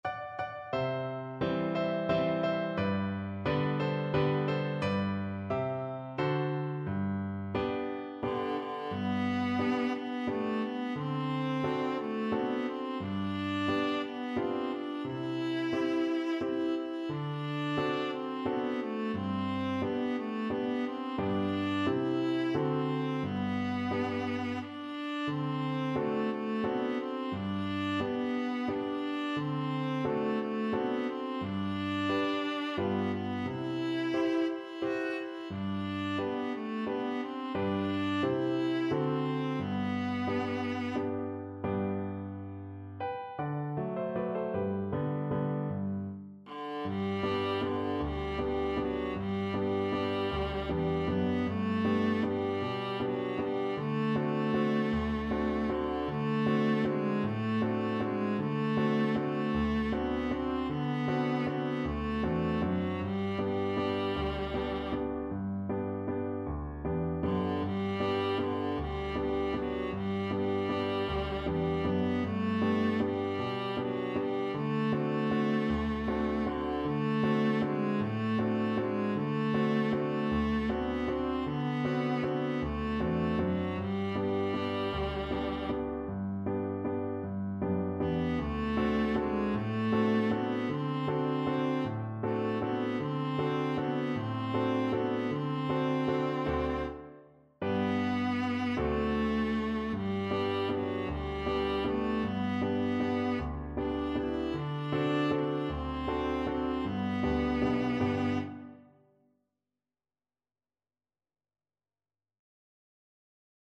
Viola version
3/4 (View more 3/4 Music)
Slow =c.88
Traditional (View more Traditional Viola Music)
Mariachi Music for Viola